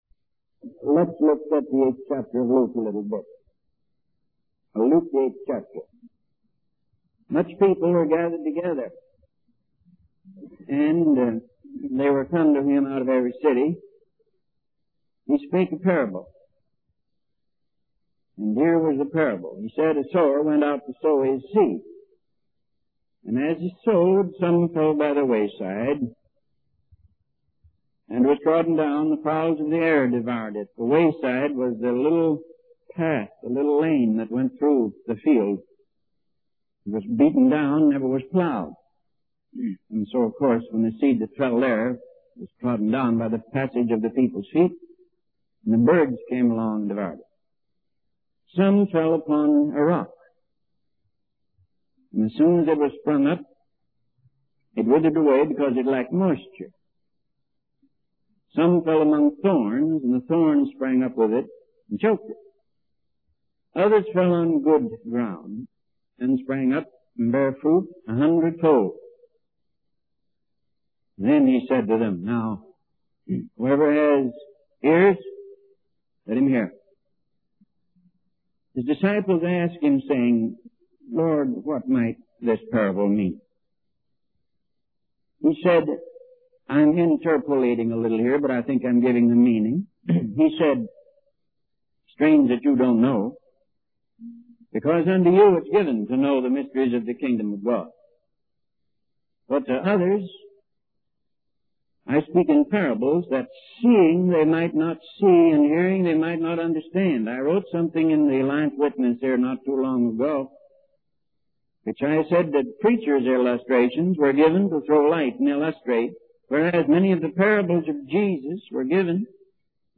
In this sermon, the preacher discusses the use of cliches in preaching and how it can lead to a lack of meaning in the Church. He emphasizes the importance of having open hearts to receive the word of God and warns against the devil taking away the seed of the gospel.